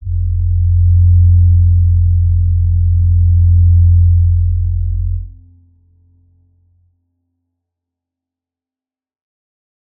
G_Crystal-F2-pp.wav